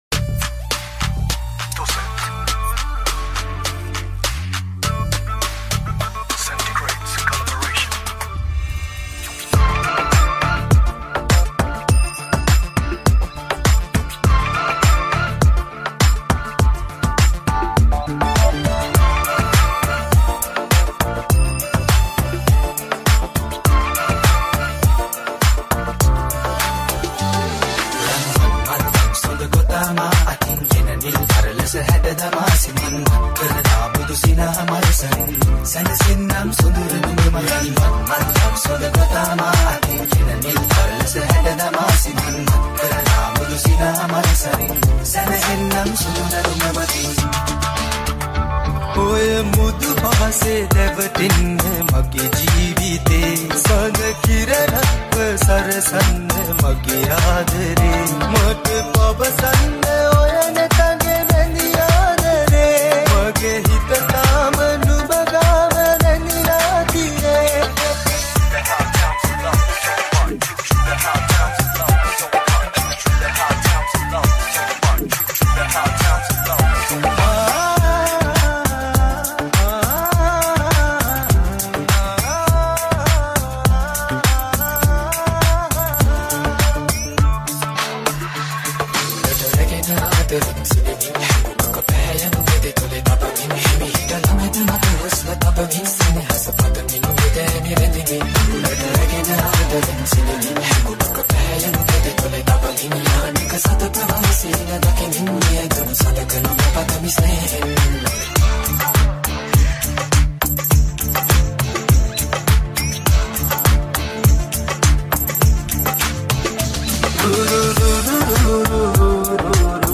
Reggatone Mix